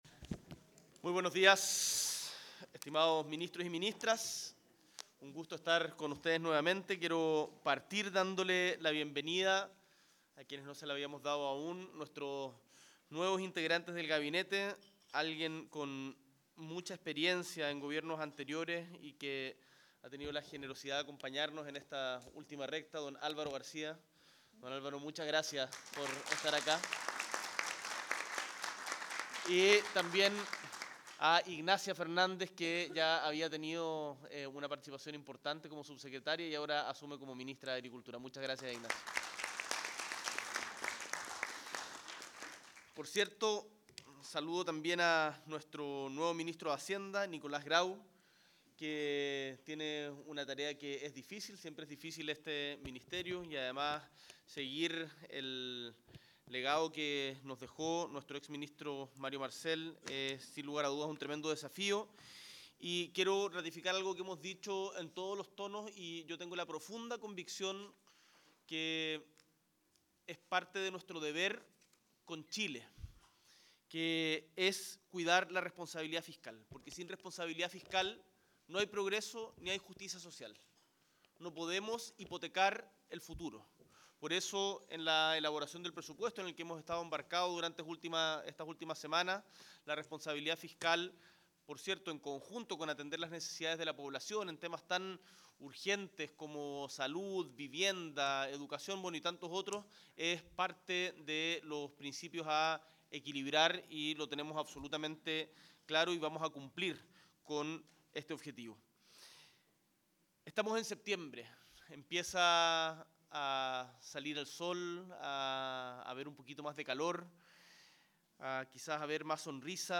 S.E. el Presidente de la República, Gabriel Boric Font, encabeza consejo de gabinete junto a ministras y ministros de Estado